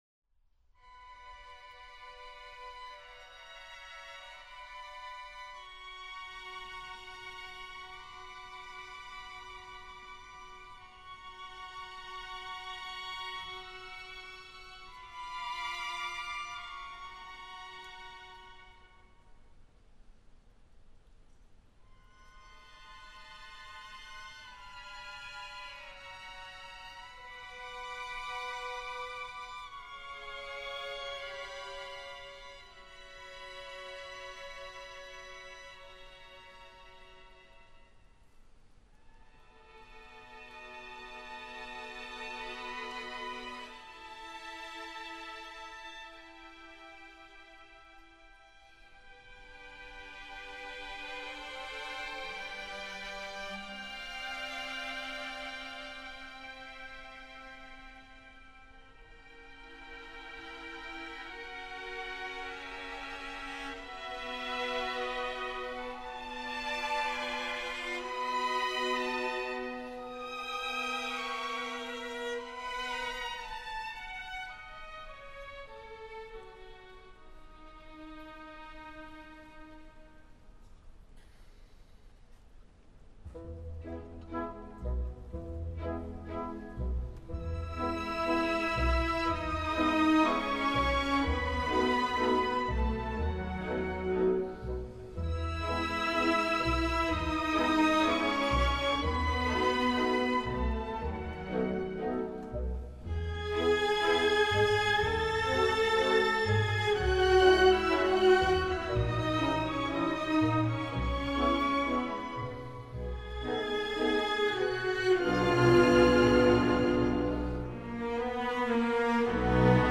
Il CD è stato registrato dall'Orchestra Sinfonica Giovanile del Piemonte dal vivo al concerto per la Festa della Repubblica, presso il teatro Alfieri di Torino (2 giugno 2005).